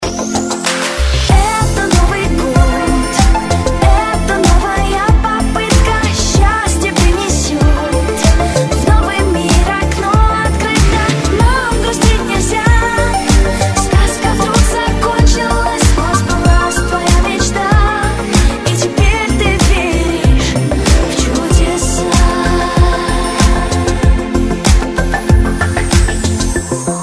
• Качество: 128, Stereo
поп
красивые
милые
сказочные